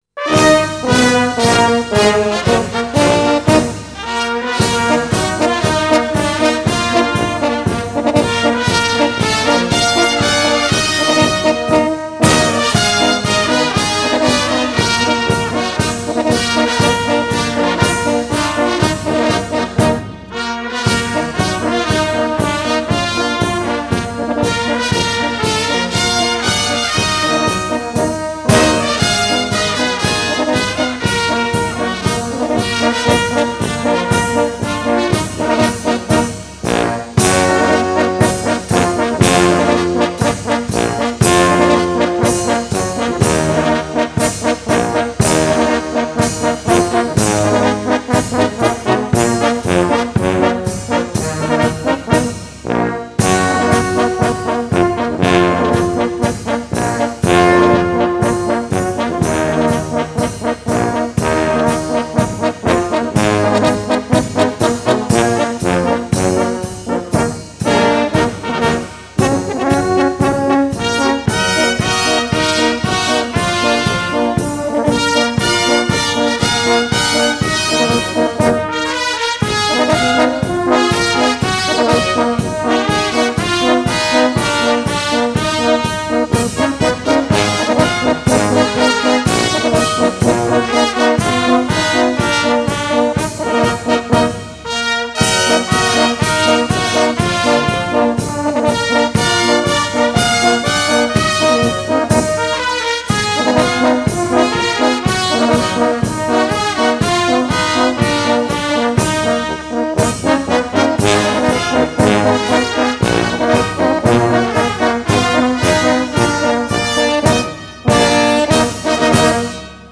Марши и фанфары - Прощание славянки (minus 2) (1).mp3